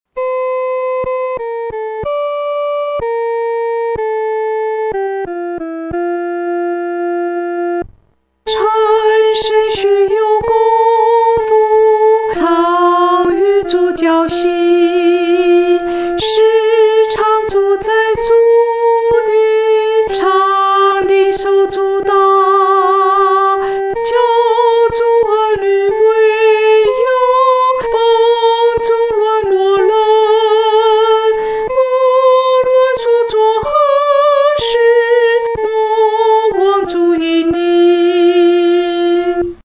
独唱（第一声）